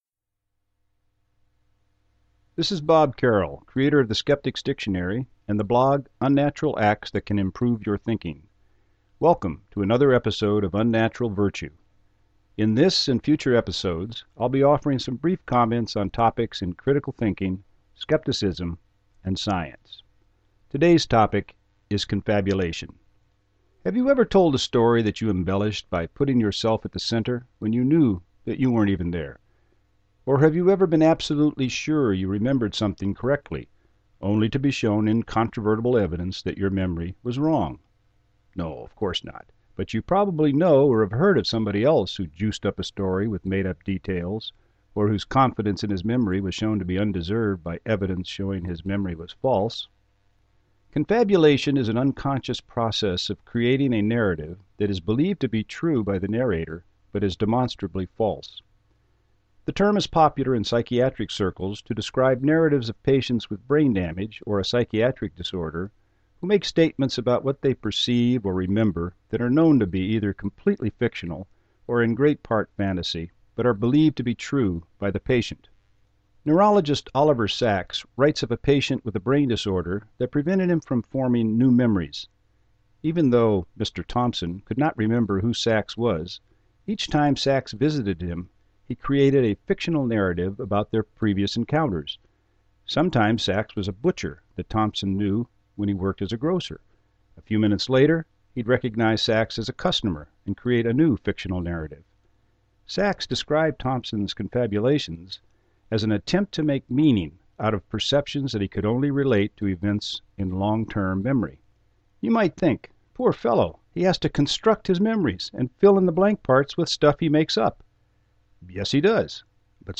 Sample from audio book The Critical Thinker's Dictionary